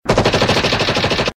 MACHINE GUN FIRING.mp3
Original creative-commons licensed sounds for DJ's and music producers, recorded with high quality studio microphones.
machine_gun_firing_qjx.ogg